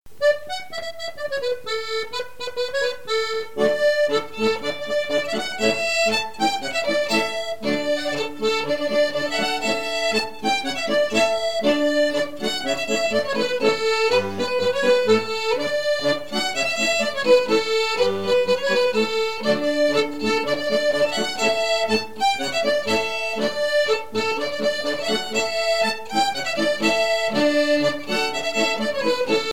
Couplets à danser
danse : branle
Pièce musicale éditée